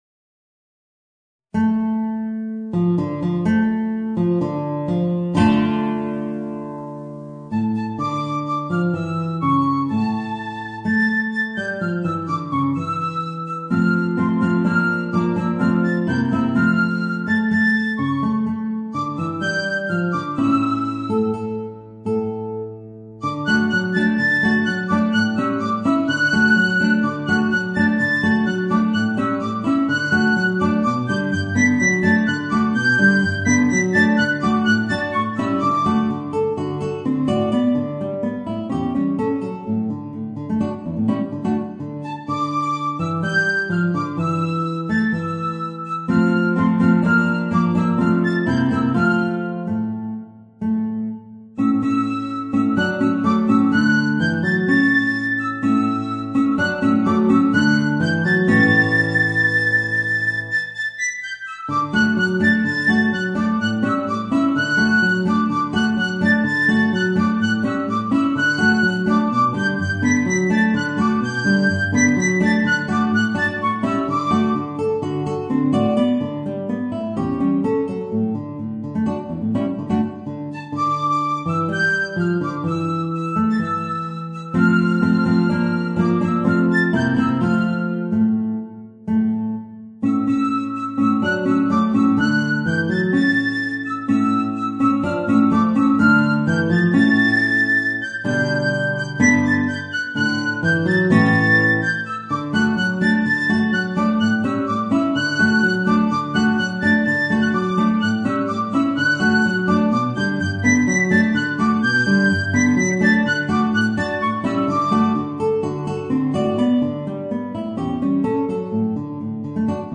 Voicing: Piccolo and Guitar